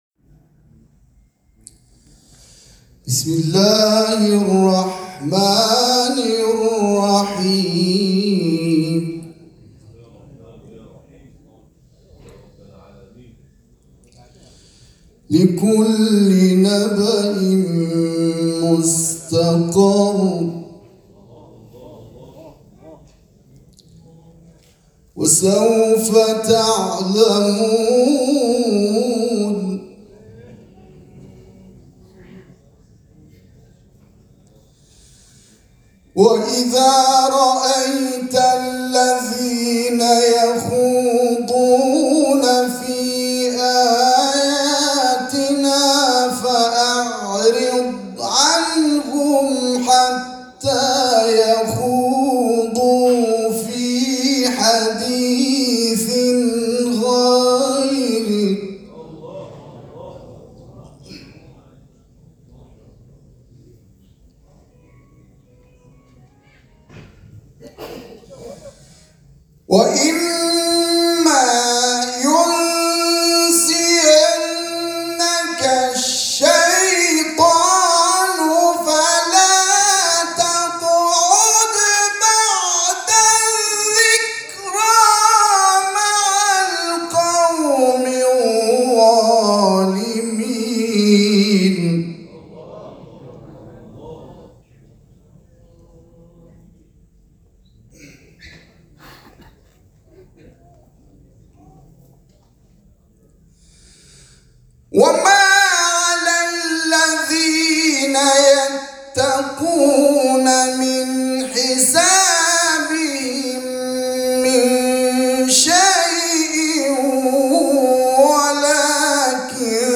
چهل و پنجمین دوره مسابقات سراسری قرآن